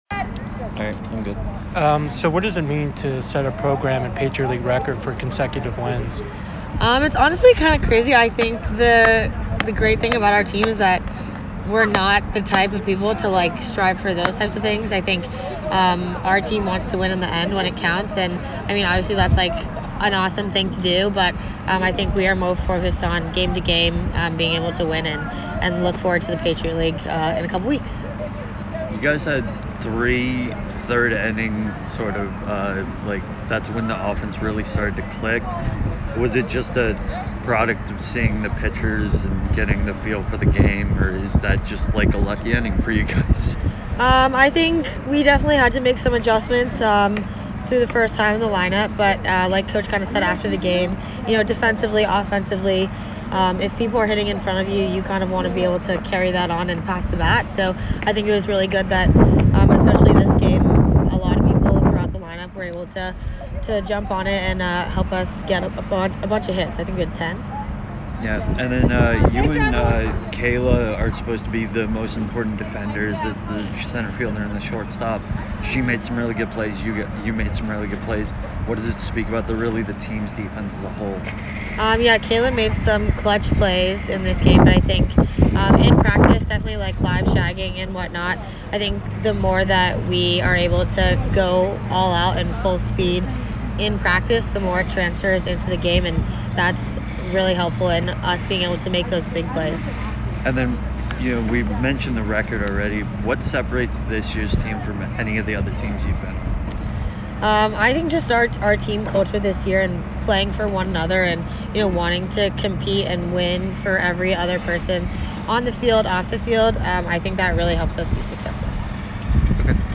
Holy Cross Softball Postgame Interview